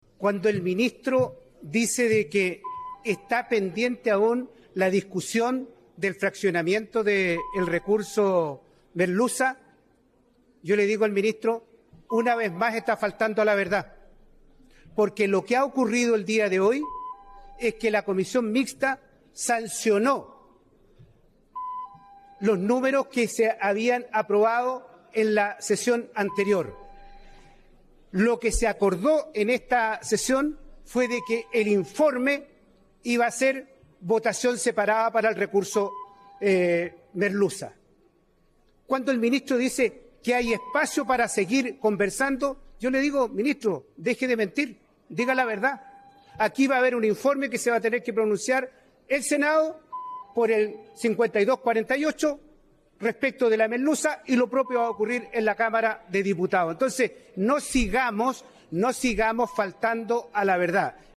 En tanto, el diputado Sergio Bobadilla (UDI) insistió en que tanto el ministro Grau como el subsecretario Salas faltaron a la verdad en el debate.